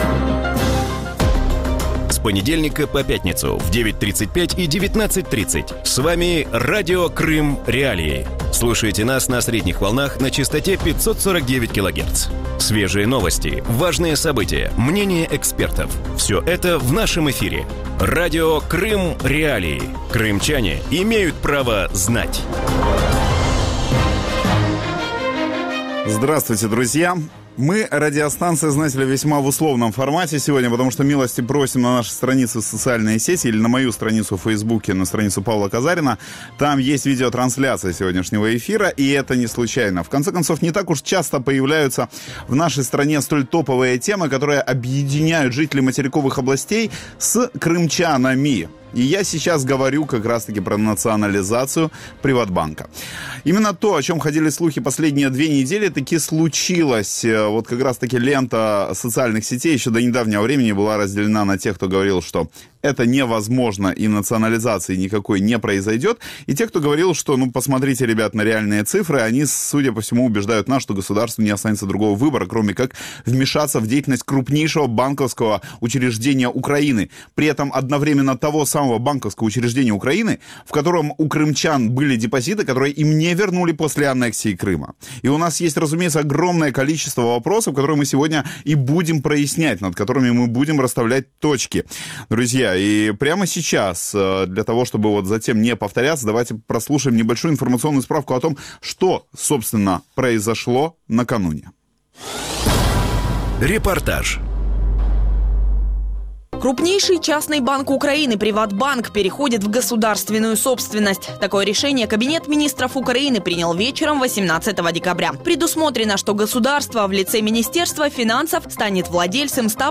В вечернем эфире Радио Крым.Реалии говорят о том, изменится ли политика «Приватбанка» по отношению к крымчанам после его национализации. Стоит ли ожидать выплат крымчанам, у которых «зависли» деньги на депозите после аннексии и что будет происходить с крупнейшим украинским банком?